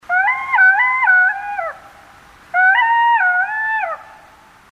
7. Шакал орет